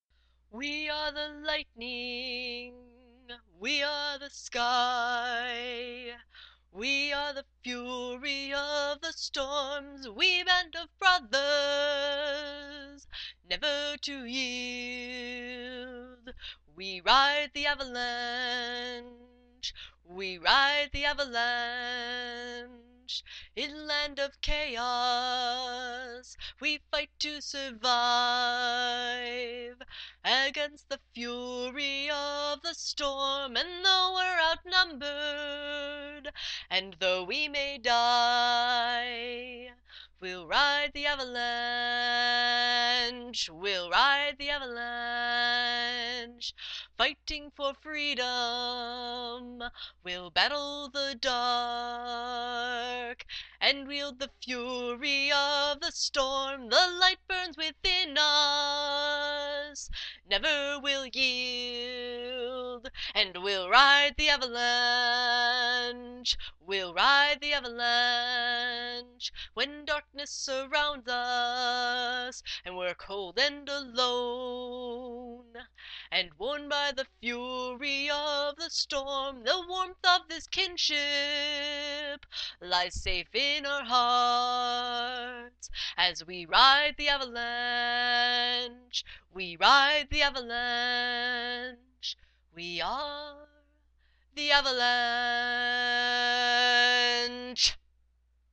Here is another war song I wrote for a LARP, but it came out nicely so I thought I would share it.